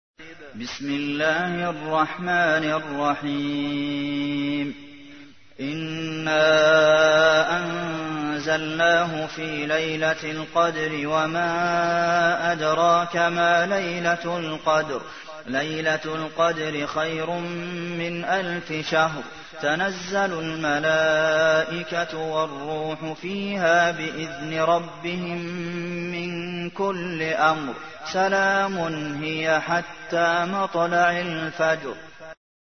تحميل : 97. سورة القدر / القارئ عبد المحسن قاسم / القرآن الكريم / موقع يا حسين